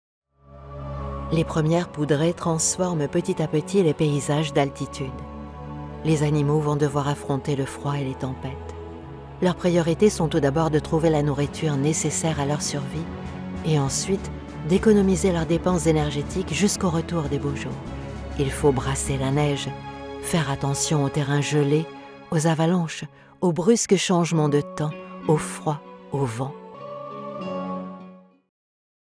She has a Voice that “speaks” to everyone, a Voice that immediately makes people feeling comfortable with, a Voice that brings everyone's attention.
Voice Tags: Reassuring, impactful, cheerful, young and fresh, authoritative, joyful, smart, trustworthy, believable, catchy, playful, educational, serious, neutral, natural, classy, upbeat...
Sprechprobe: Sonstiges (Muttersprache):
Narration-Quatre-Saisons-Hiver.mp3